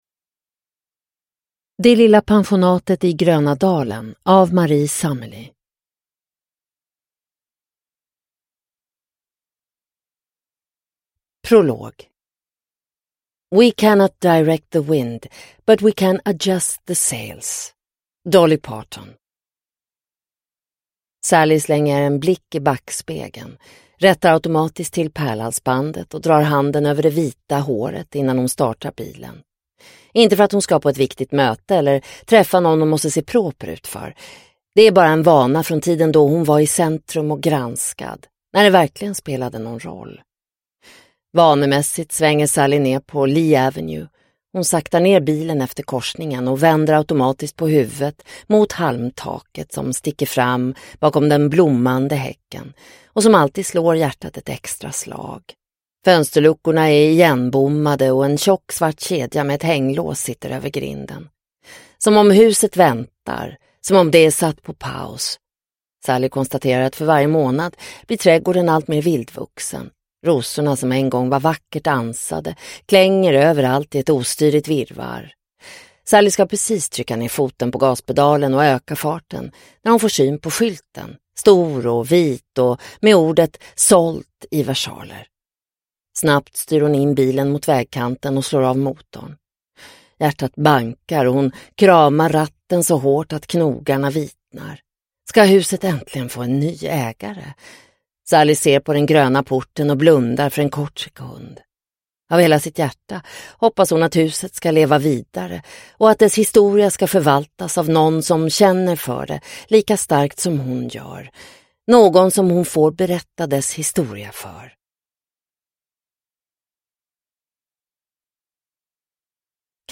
Det lilla pensionatet i gröna dalen – Ljudbok – Laddas ner